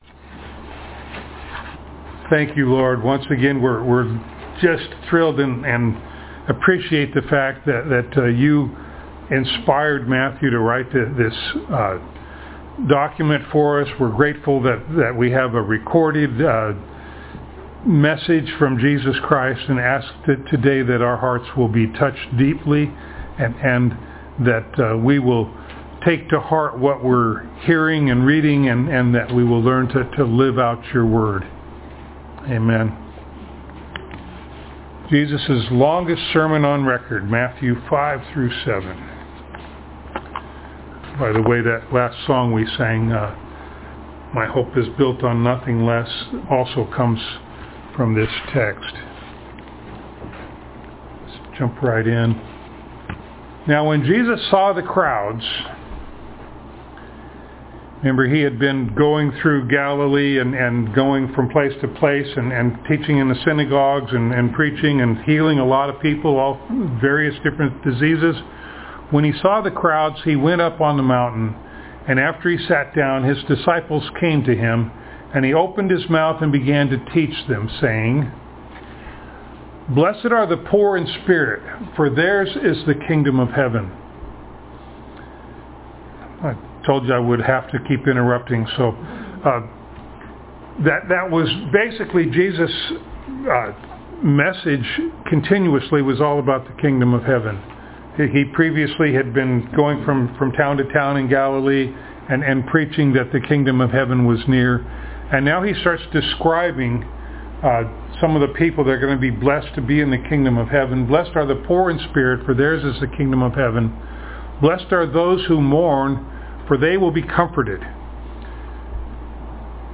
Passage: Matthew 5-7 Service Type: Sunday Morning Download Files Notes « The Man